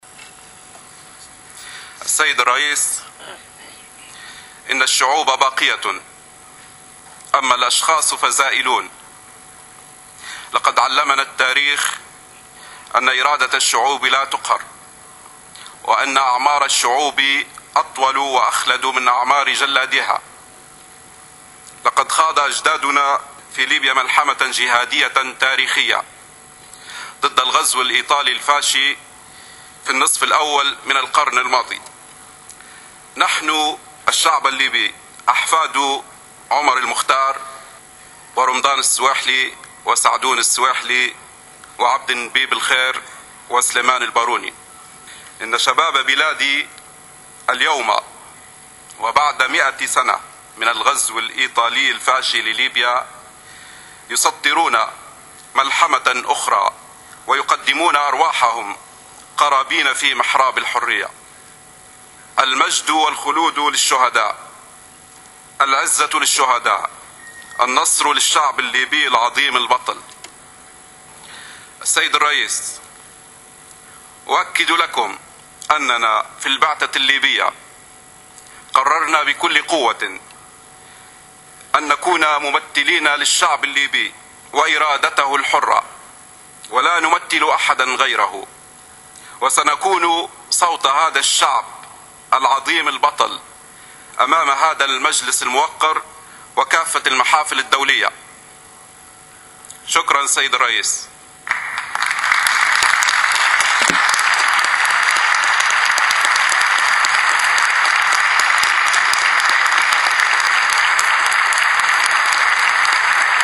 مداخلة قصيرة أمام الجلسة الخاصة لمجلس حقوق الإنسان حول الأوضاع في ليبيا